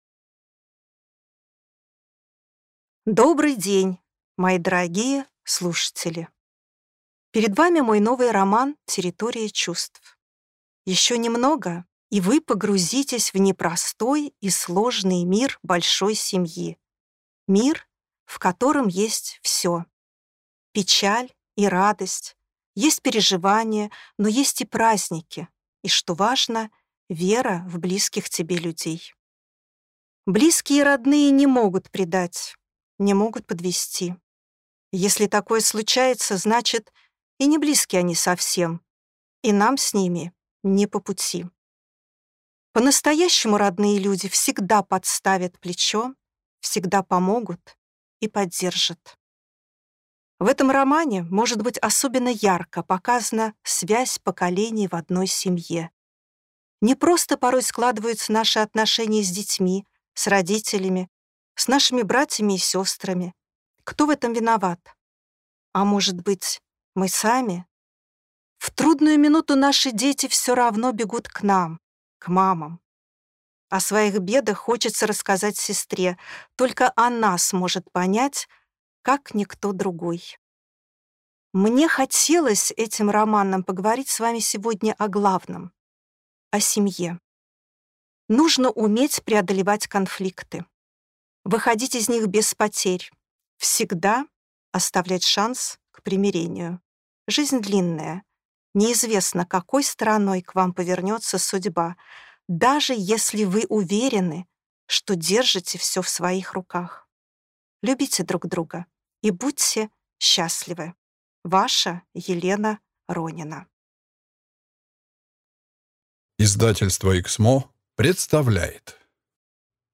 Аудиокнига Территория чувств | Библиотека аудиокниг